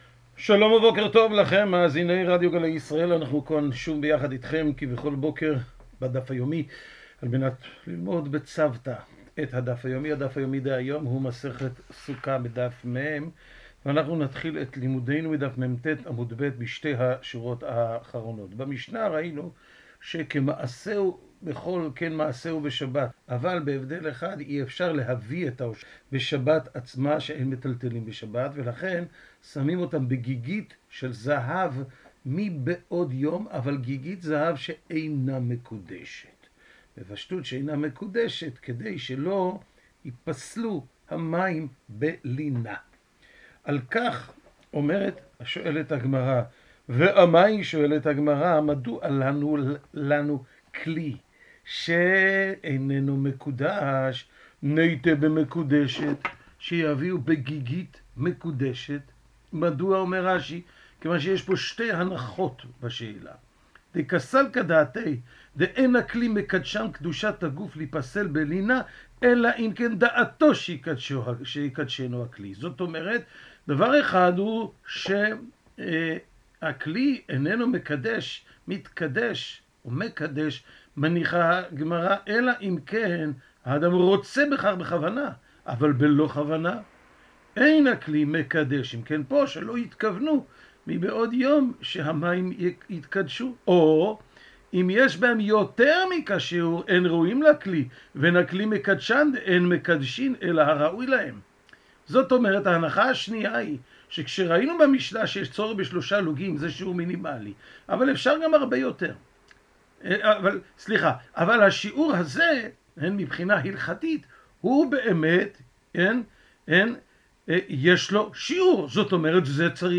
השיעור משודר בשעה 05:30 בבוקר ברדיו גלי ישראל וכל היום באתר סרוגים.